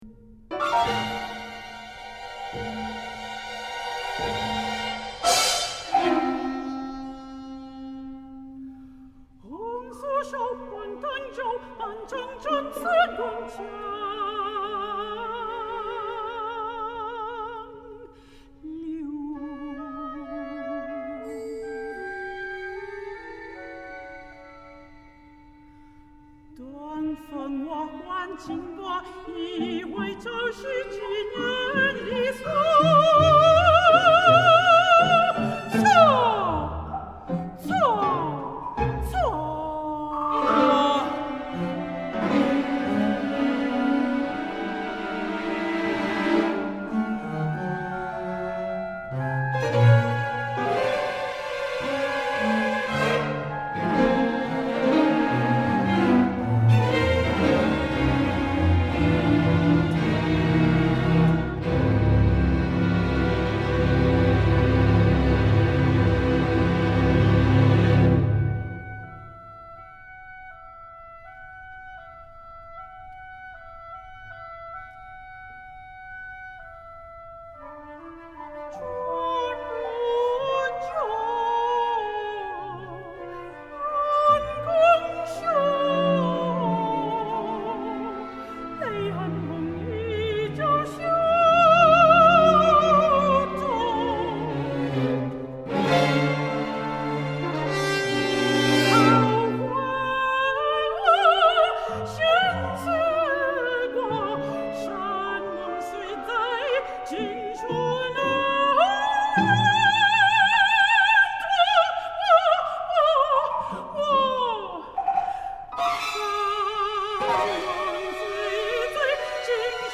for Soprano and Chamber Orchestra (13 players)